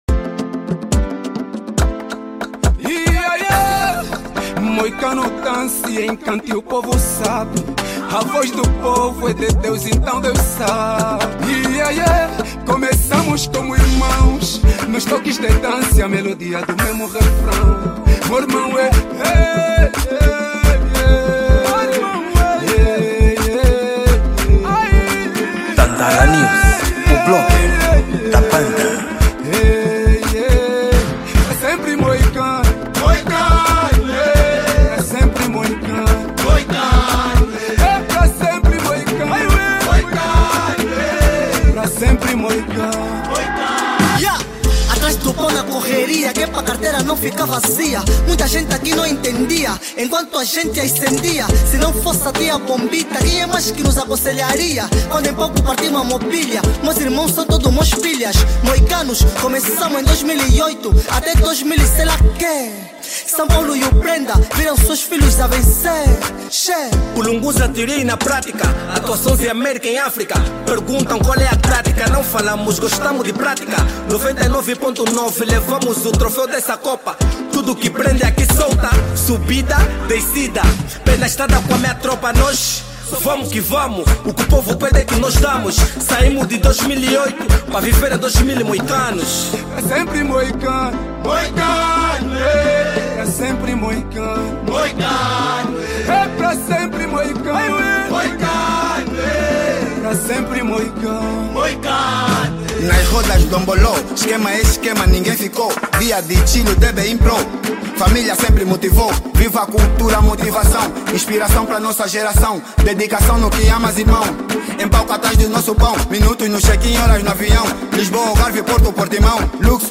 Gênero: Afro Trap